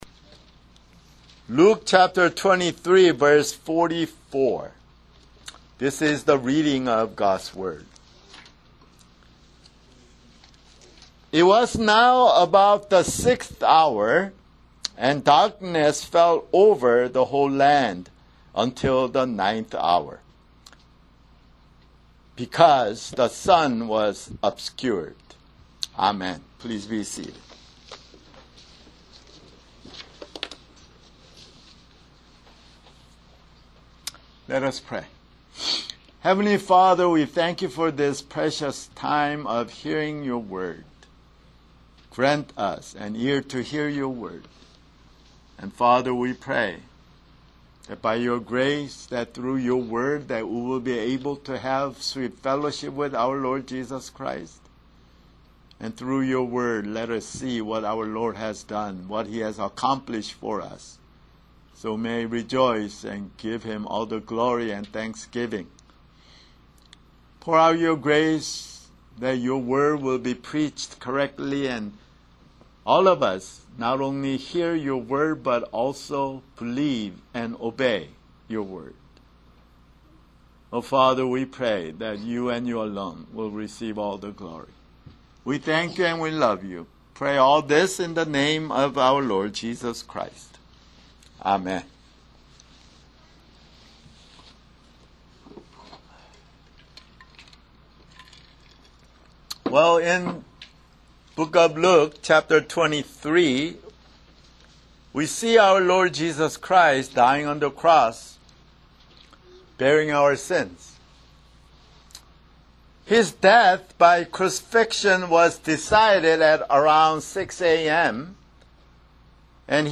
[Sermon] Luke (169)